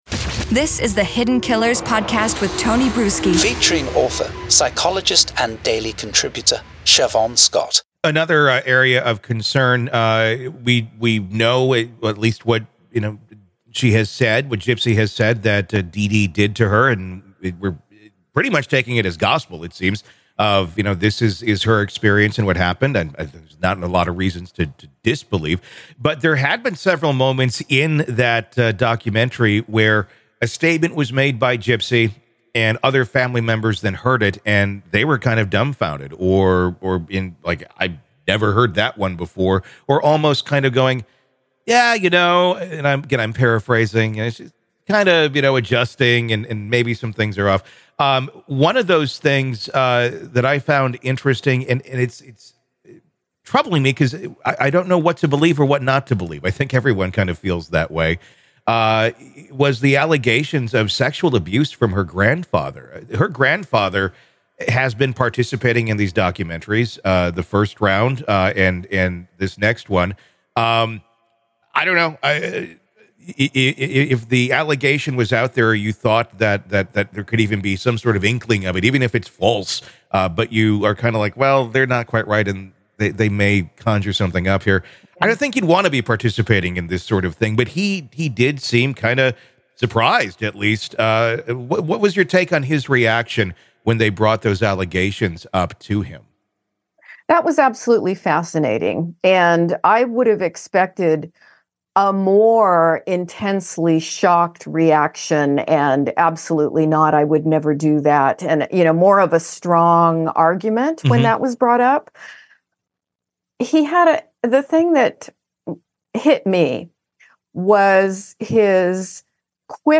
True Crime Today | Daily True Crime News & Interviews / How Will Gypsy Rose Find Her Way In A Free World?